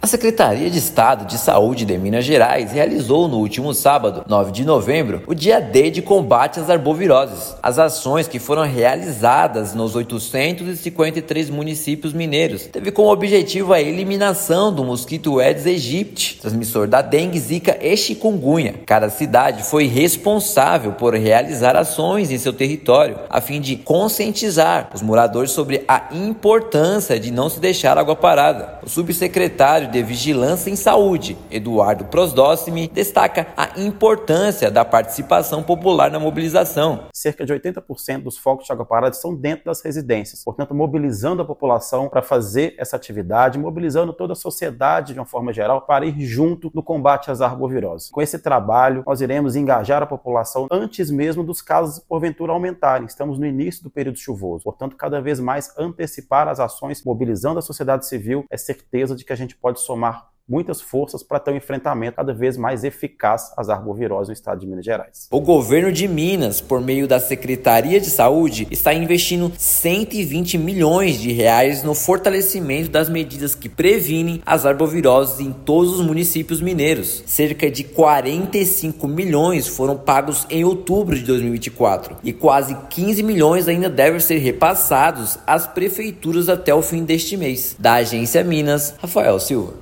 Municípios em todo o estado passaram por mutirões de limpeza, vistorias e campanhas educativas para conscientizar a população sobre a importância de eliminar os focos do Aedes aegypti. Ouça matéria de rádio.